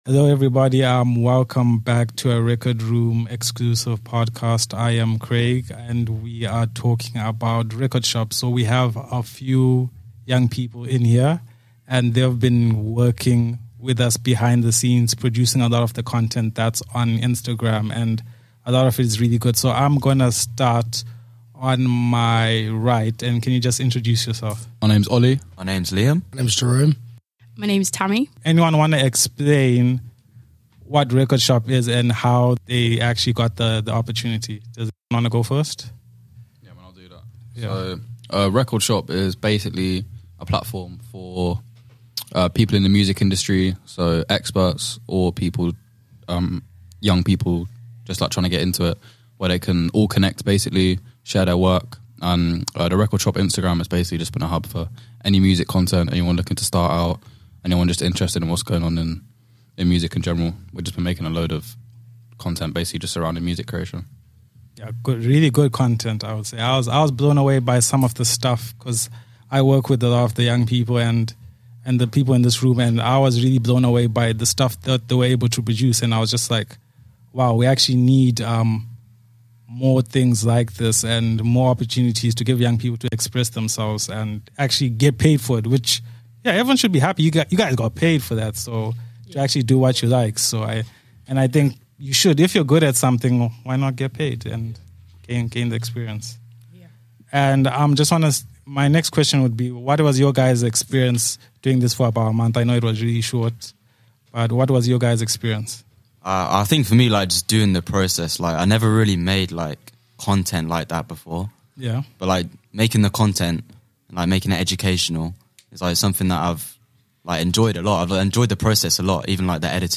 PEER-LEADERS-REKORD-SHOP-DISCUSSION-MSRCH.mp3